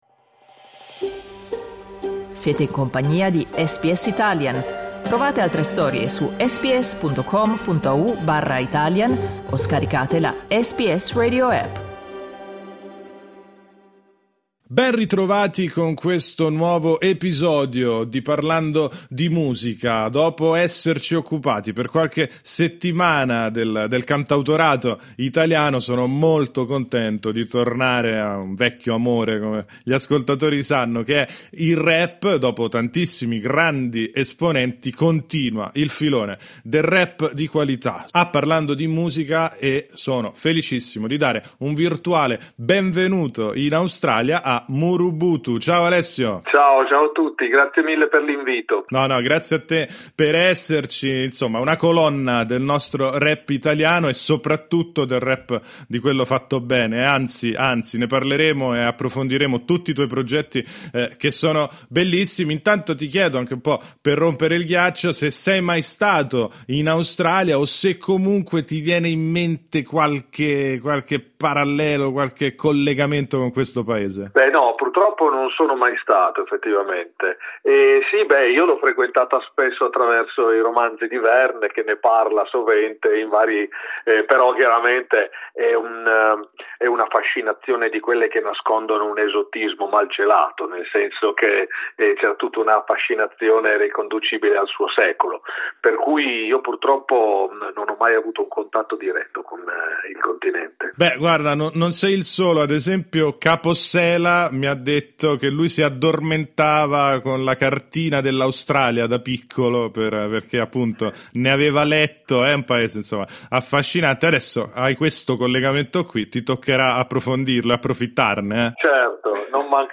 Ospite a Parlando di Musica, il rapper svela a SBS Italian anche l’uscita del nuovo album, previsto per l’inizio del 2025.